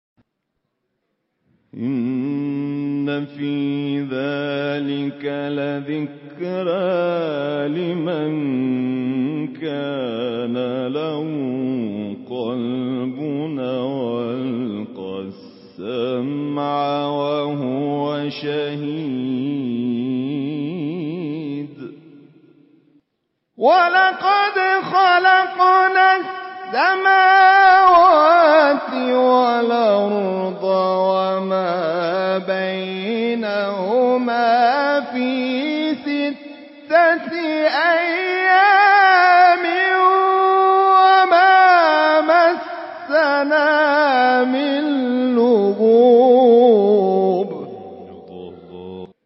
گروه شبکه اجتماعی: فرازهای صوتی از تلاوت قاریان بنام و ممتاز کشور را می‌شنوید.
اجرا شده در مقام حجاز